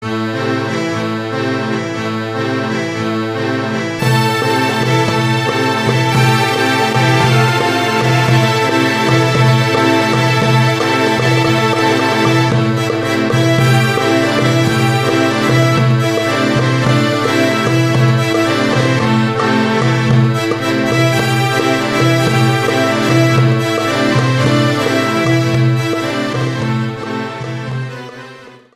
MASTOUM MASTOUM - upbeat Persian